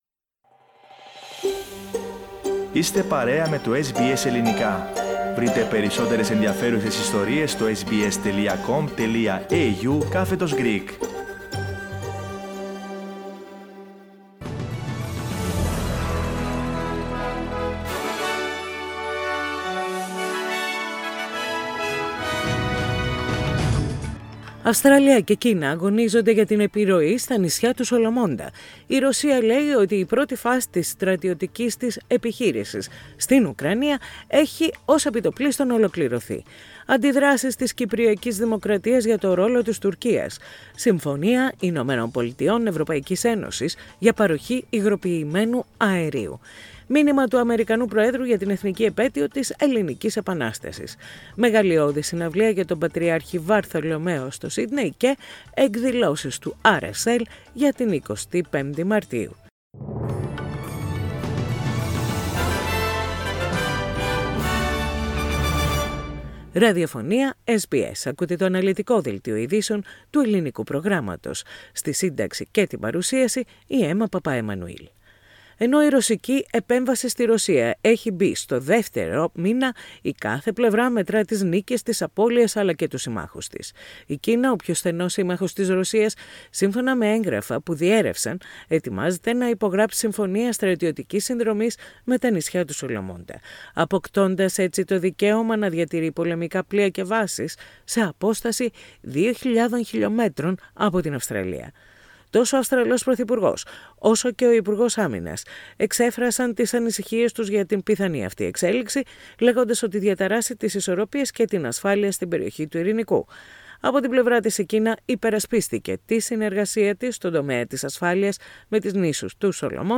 Δελτίο Ειδήσεων - Σάββατο 26.3.22
News in Greek.